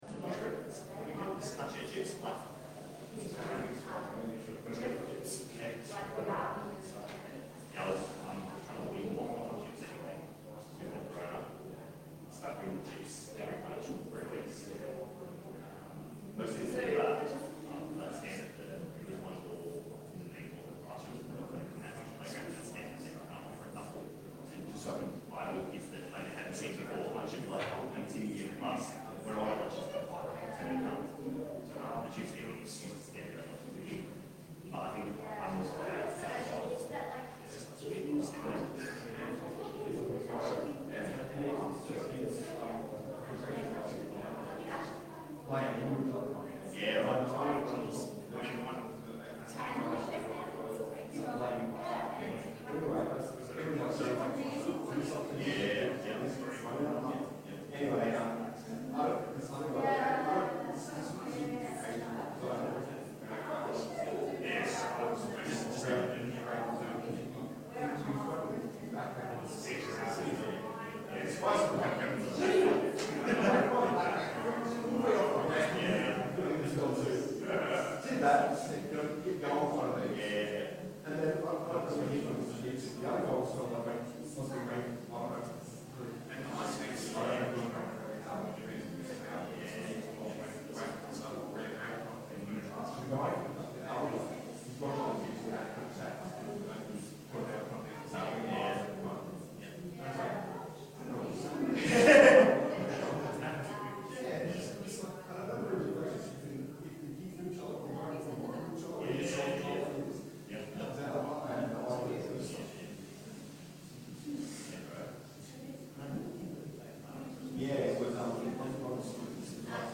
Service Type: Rosemeadow AM